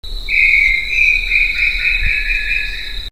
BROWN TINAMOU Crypturellus obsoletus
For the most part the only clue that Brown Tinamous are in the area are their loud, rising calls, likened to a series of policeman whistles.
Short call  recorded PROCOSARA, PN San Rafael
Crypturellusobsoletus(2).wav